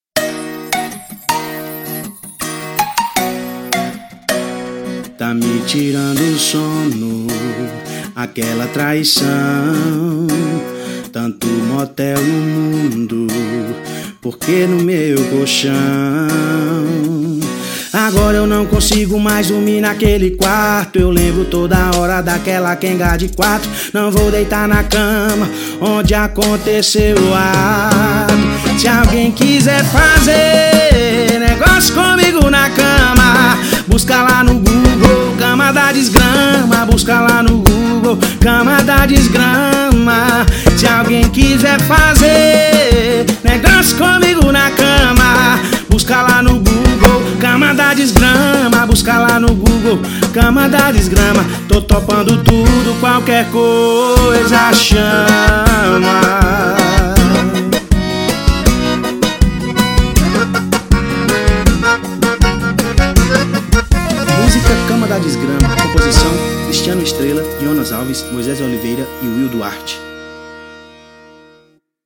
Sertanejo Romântico